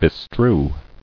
[be·strew]